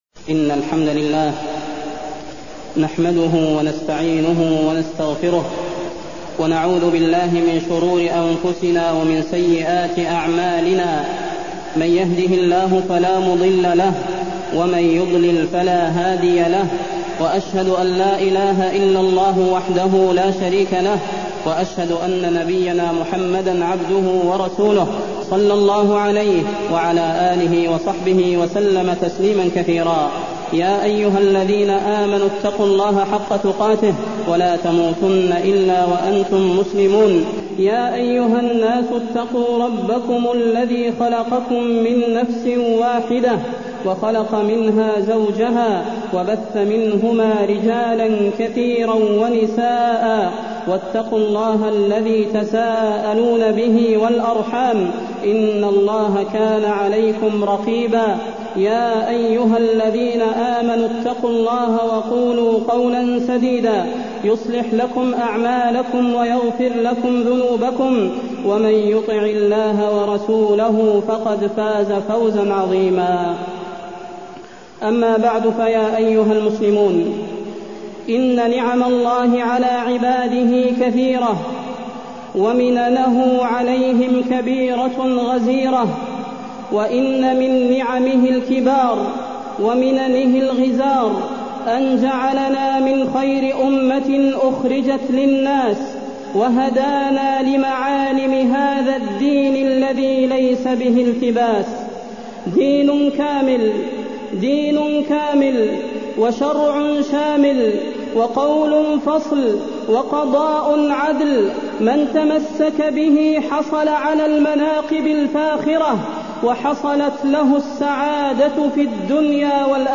تاريخ النشر ١٠ شعبان ١٤٢٢ هـ المكان: المسجد النبوي الشيخ: فضيلة الشيخ د. صلاح بن محمد البدير فضيلة الشيخ د. صلاح بن محمد البدير إنتصار الإسلام وعلوه